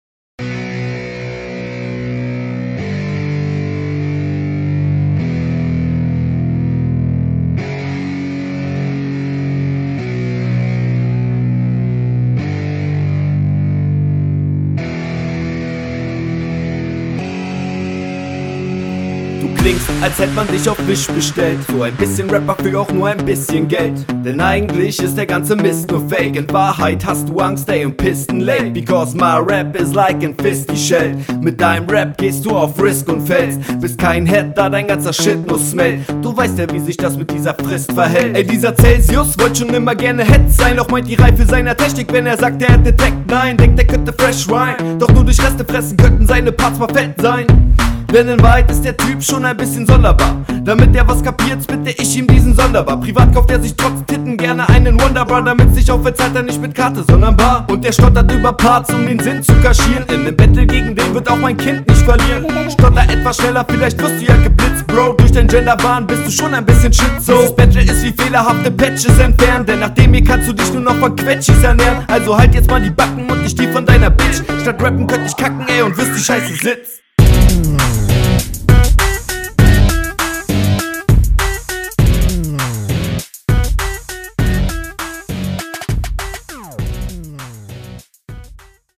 Flow kommt hier deutlich routinierter. Betonungen sitzen auch.
Geiler Beat, cooler Flow, hört sich für mich sehr gut an.